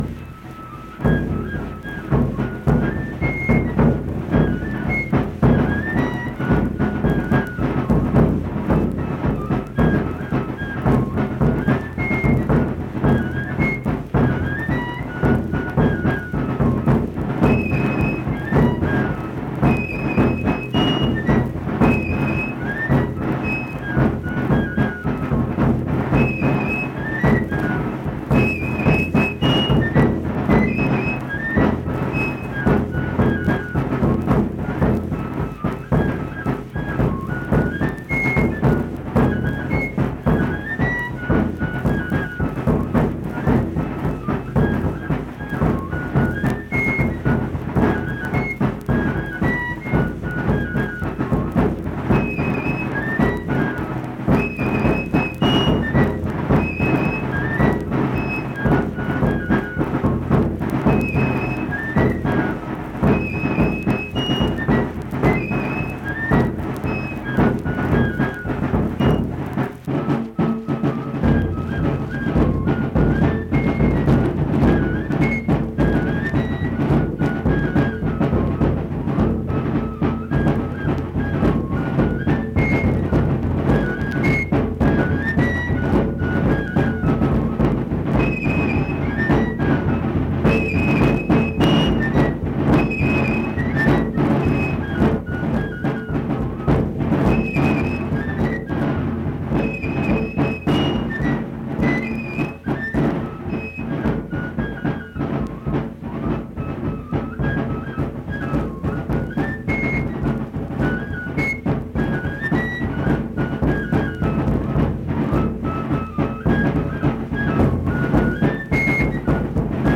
Accompanied fife and drum music
Verse-refrain 2(4). Performed in Hundred, Wetzel County, WV.
Instrumental Music
Drum, Fife